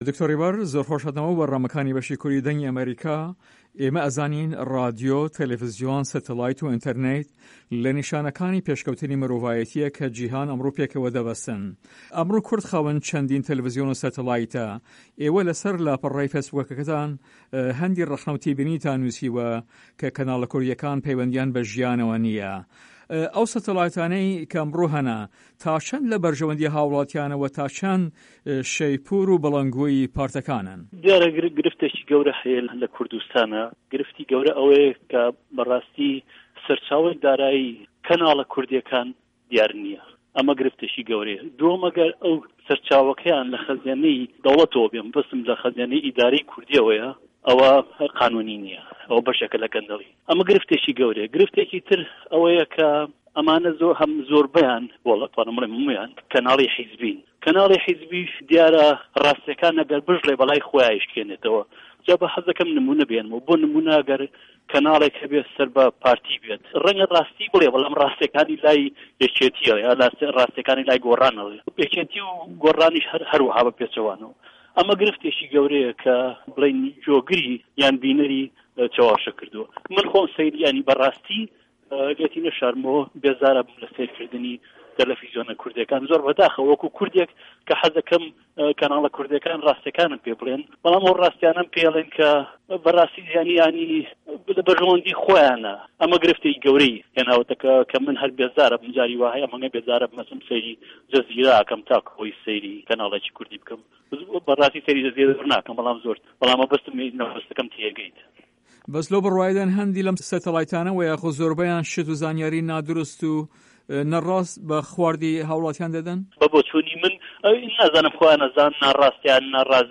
هه‌رێمه‌ کوردیـیه‌کان - گفتوگۆکان